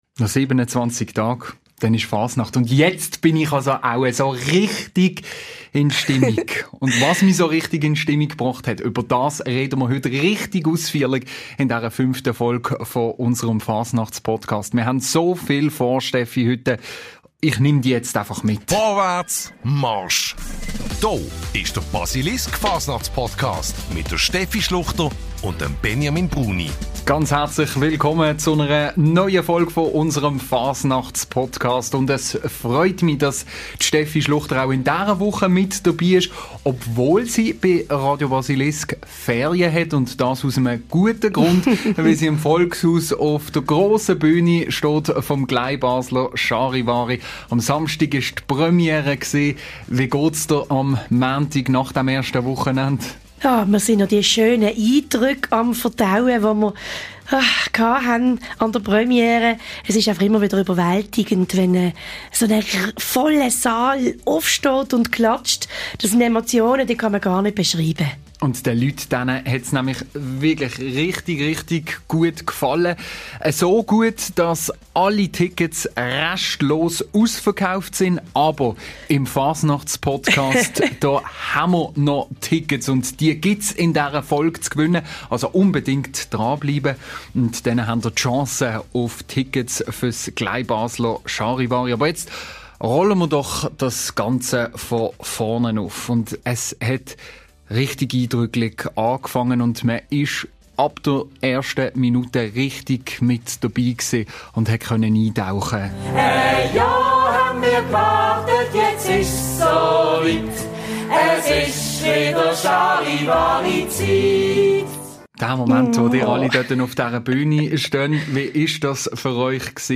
Es gibt Zuschauerstimmen, welche unmittelbar nach der Premiere aufgenommen wurden aber auch Grussbotschaften von gestandenen Persönlichkeiten. Es wird aber auch über das übrige Bühnenprogramm gesprochen welches zurecht abgefeiert wird.